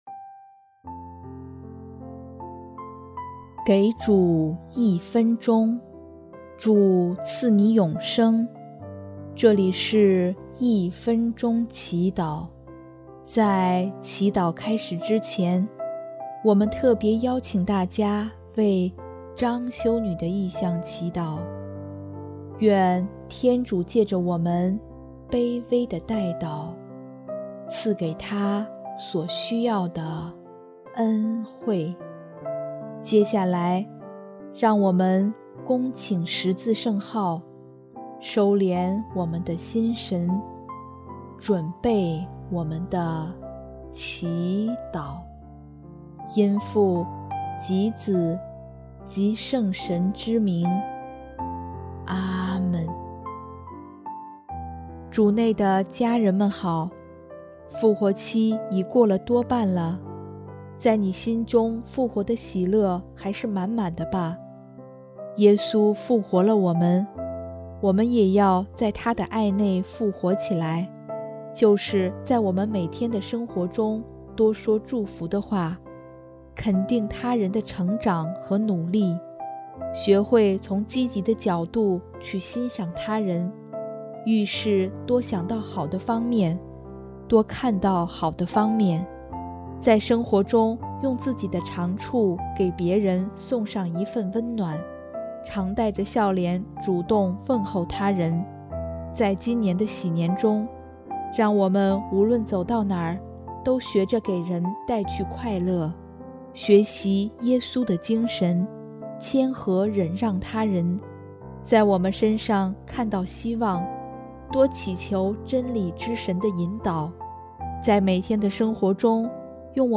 【一分钟祈祷】|5月28日 多多祝福他人
音乐：第四届华语圣歌大赛参赛歌曲《我一心敬拜你》